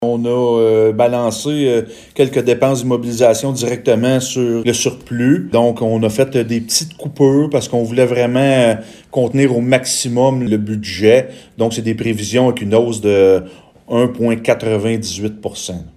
Le maire de Gracefield, Mathieu Caron, précise comment la Ville est parvenue à équilibrer son budget :